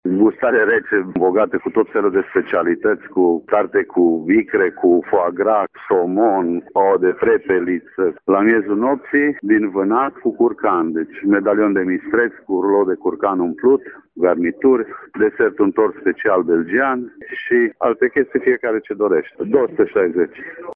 Tombolele și meniurile speciale nu lipsesc nici aici, spune șeful de restaurant